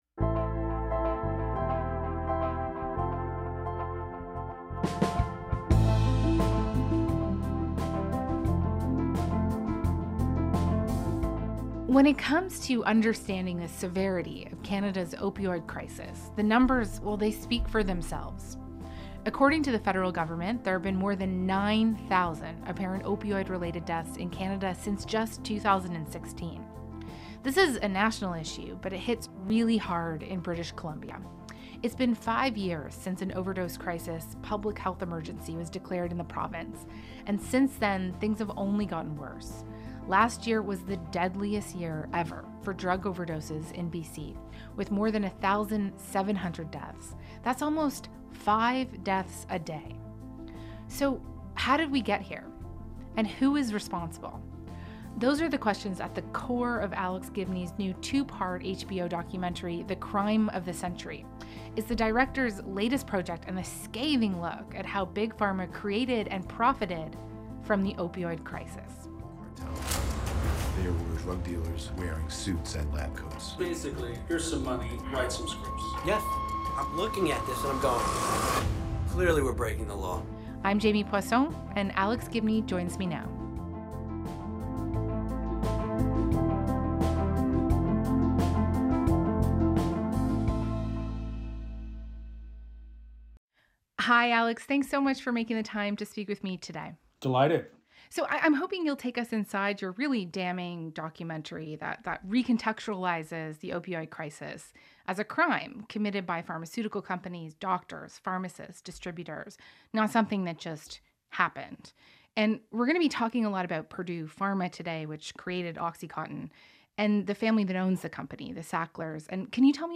File Information Listen (h:mm:ss) 0:21:56 Front_Burner_interview_Alex_Gibney_May_17_2021.wav Download (14) Front_Burner_interview_Alex_Gibney_May_17_2021.wav 63,212k 48kbps Stereo Listen All